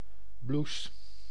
Ääntäminen
IPA: /blus/